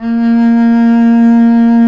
Index of /90_sSampleCDs/Roland - String Master Series/STR_Cb Bowed/STR_Cb2 f vb